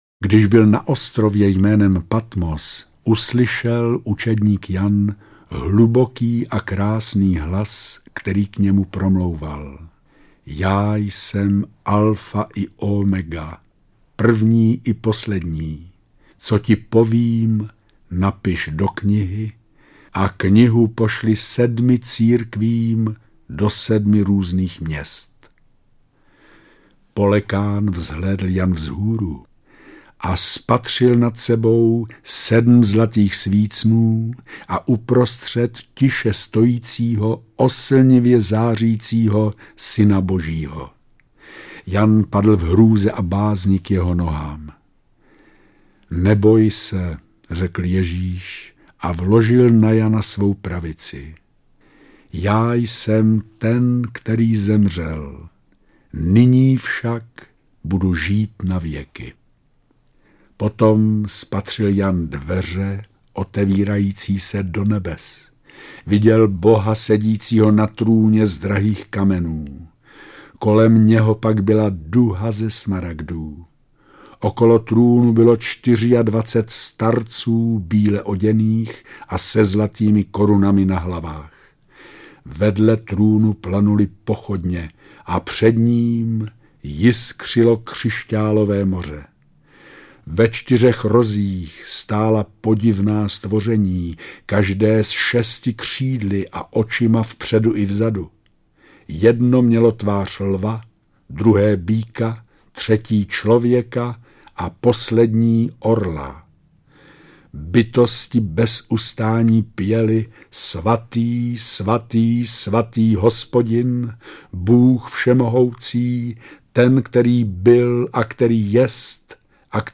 Slova útěchy audiokniha
Ukázka z knihy
Známé texty z oblasti duchovní literatury jsou inspirací pro chvíle tichého rozjímání. Hlasy významných českých osobností nás posilují na naší další cestě.
• InterpretJosef Somr, Naďa Konvalinková, Vladimír Javorský, Kardinál Miloslav Vlk